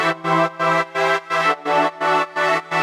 Index of /musicradar/sidechained-samples/170bpm
GnS_Pad-MiscB1:4_170-E.wav